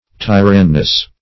Search Result for " tyranness" : The Collaborative International Dictionary of English v.0.48: Tyranness \Ty"ran*ness\, n. A female tyrant.